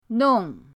nong4.mp3